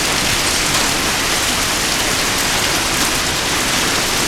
FX  RAIN 0JR.wav